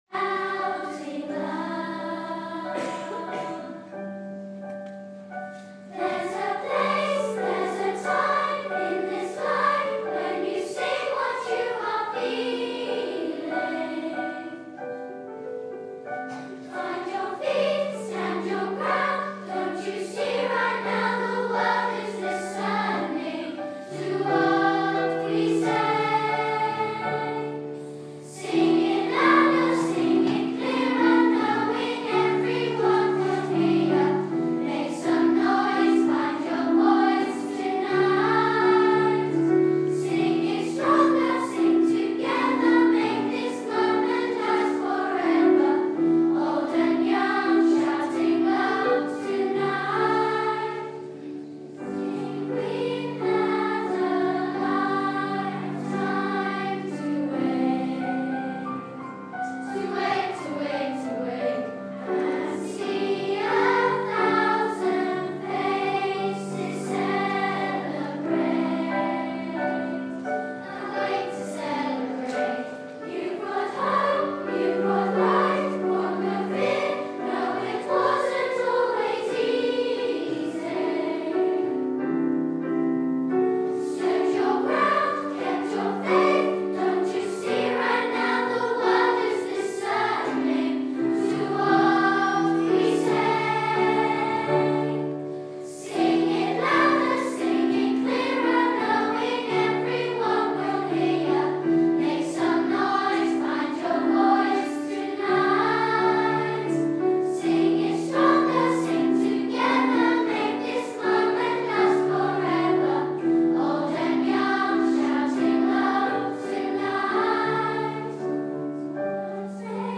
Children singing